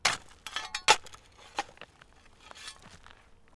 FreeGardenSFX_Digging.wav